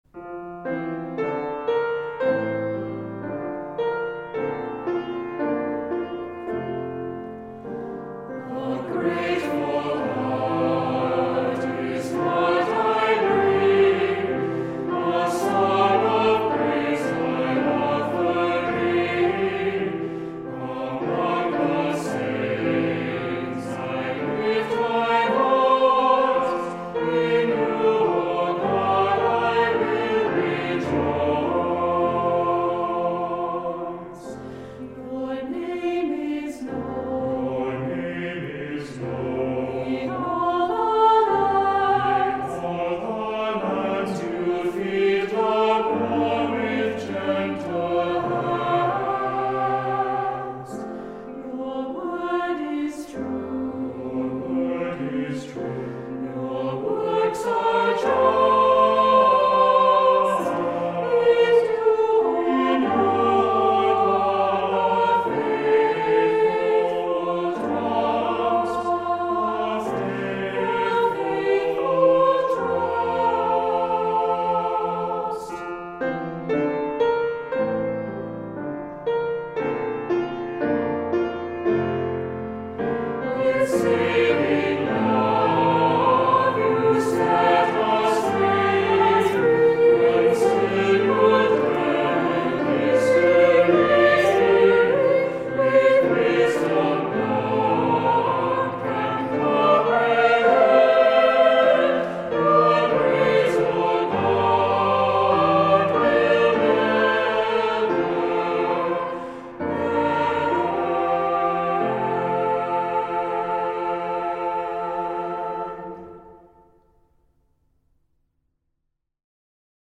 Voicing: Two-part mixed; Assembly